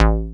bseTTE48011moog-A.wav